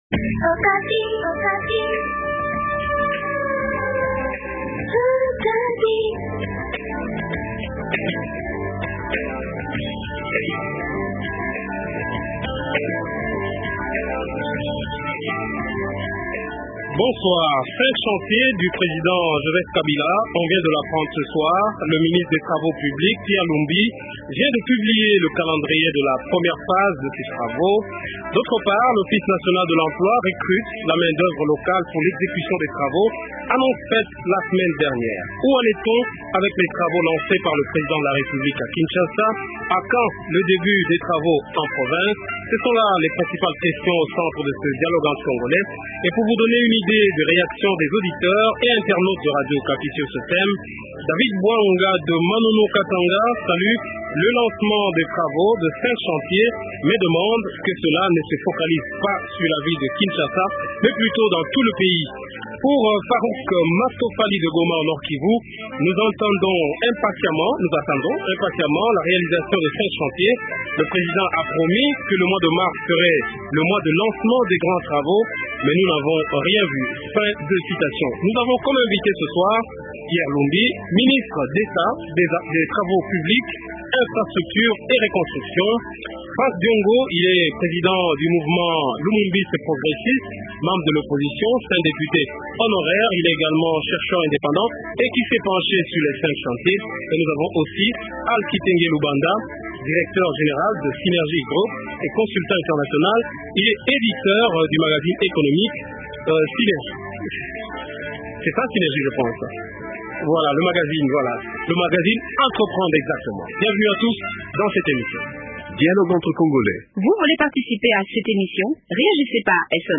Pierre Lumbi, ministre des infrastructures, travaux publics et reconstruction - Franck Diongo, Président du mouvement lumumbiste progressiste/ opposition